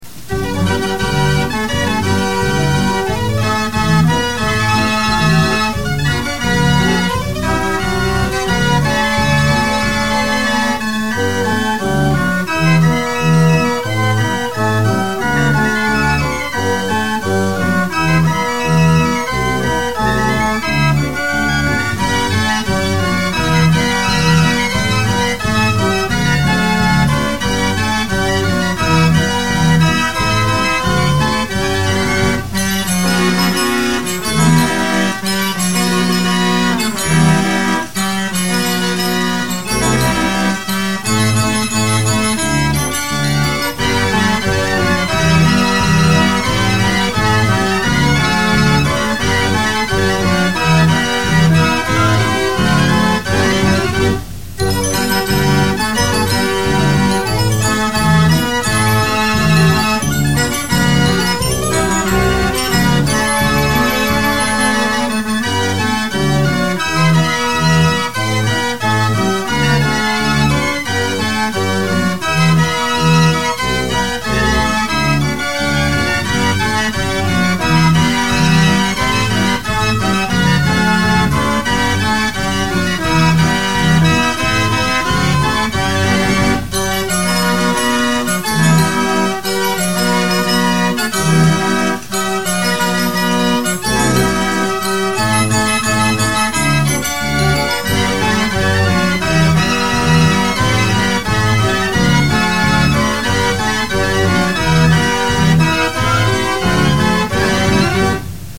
Fox Trot